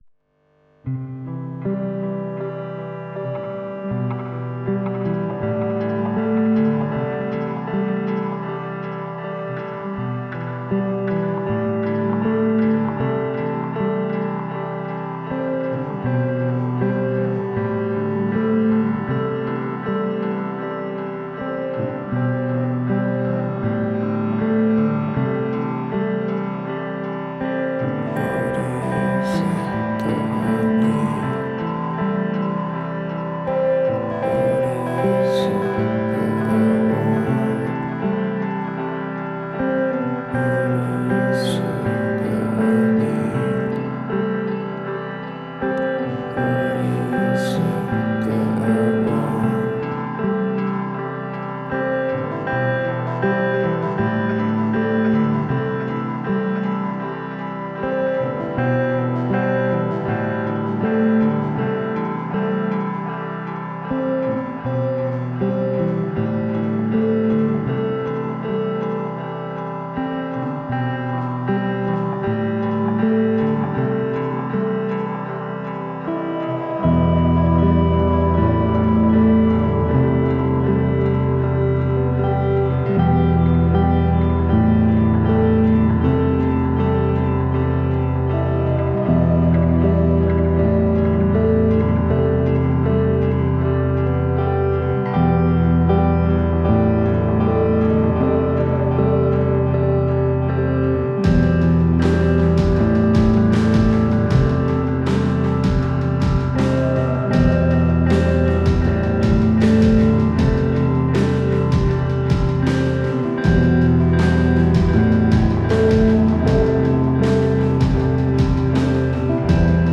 une rythmique sourde, fatiguée mais sereine